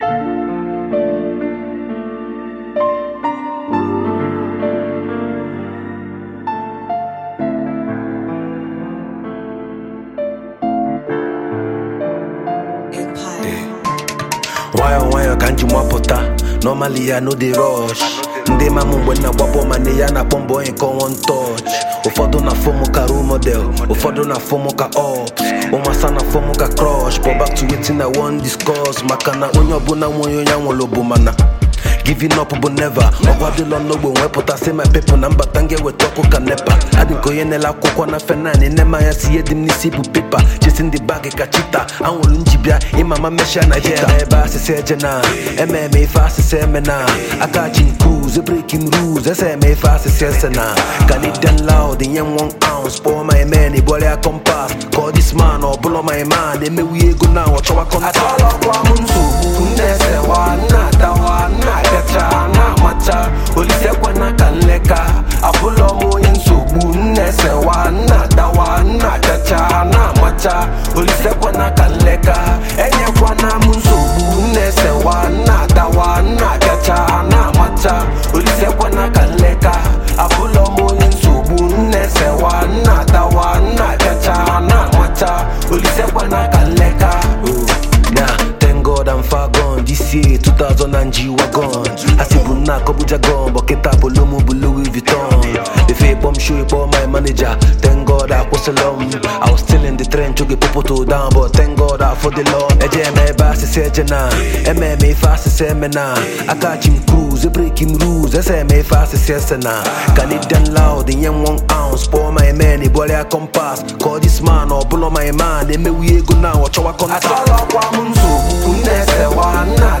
Foreign MusicNaija Music